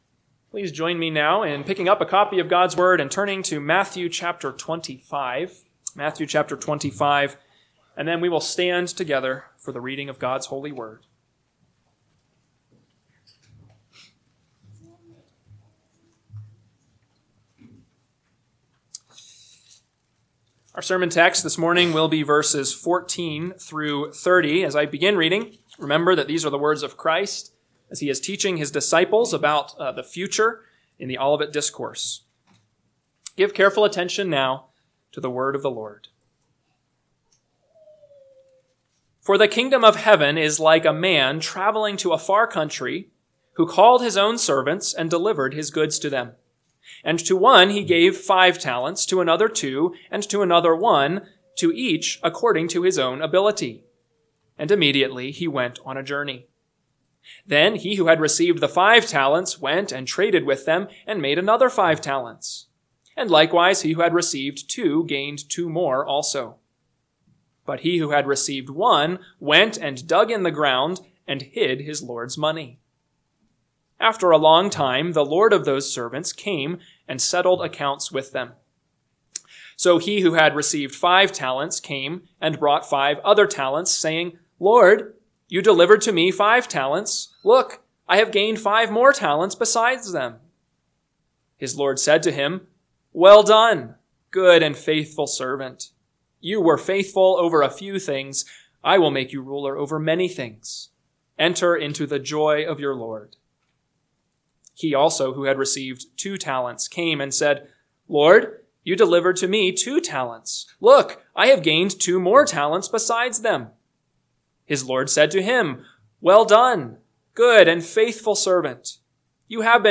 AM Sermon – 1/26/2025 – Matthew 25:14-30 – Northwoods Sermons